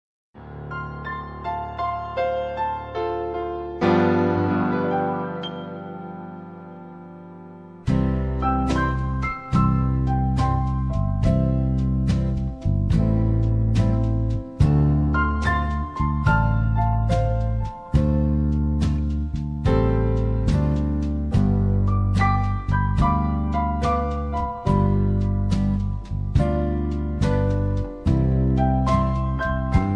karaoke, mp3 backing tracks